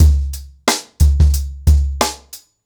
TrackBack-90BPM.69.wav